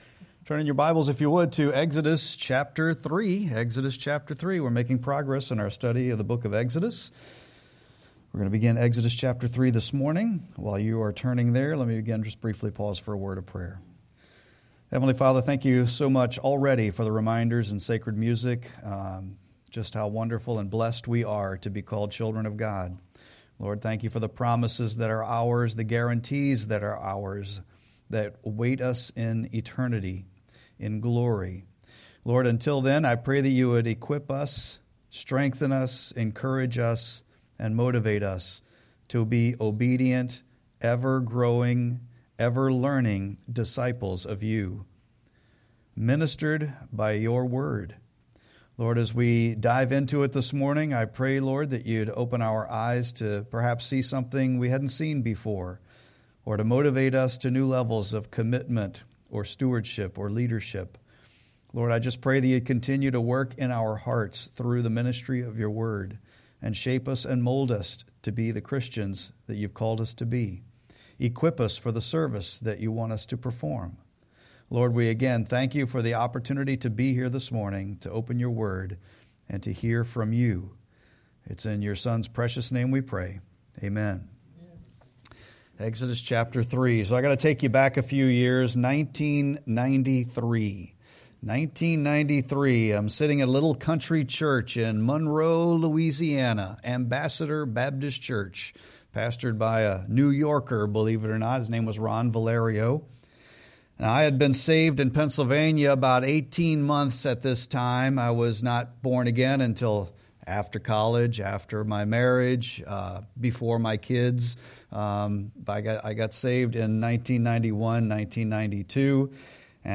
Sermon-2-8-26.mp3